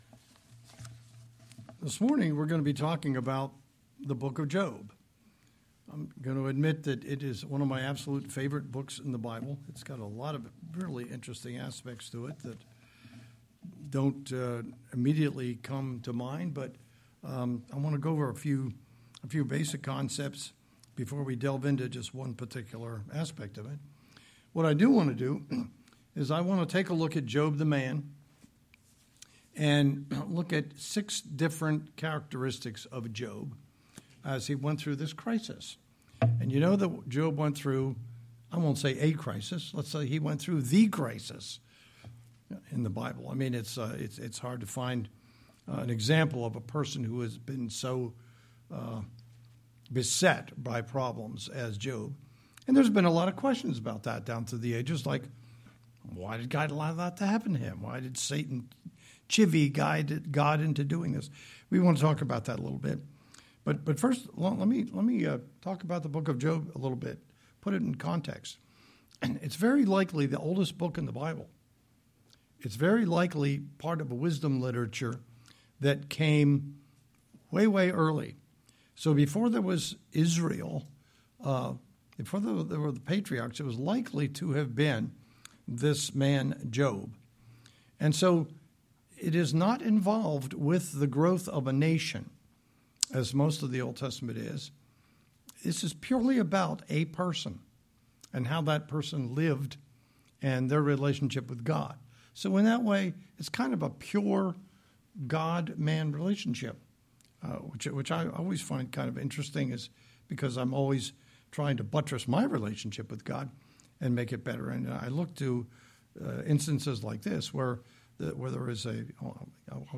Sermon-6-8-25.mp3